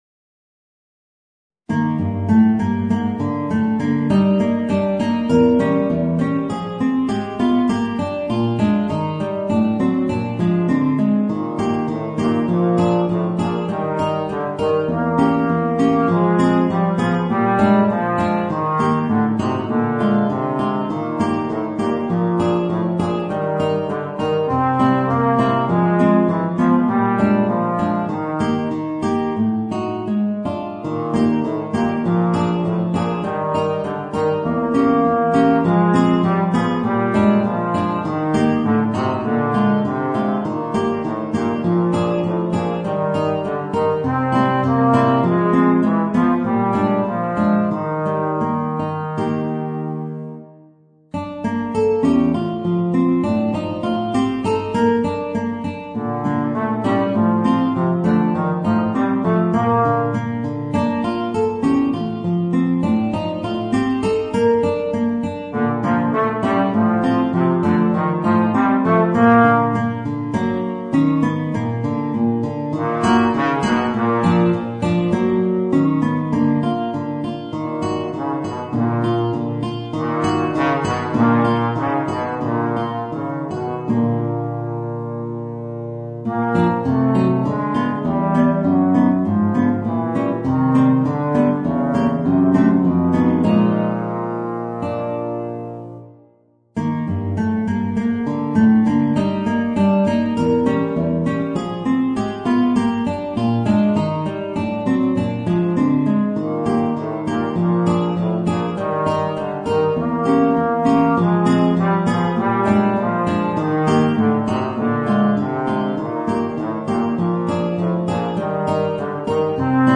Voicing: Bass Trombone and Guitar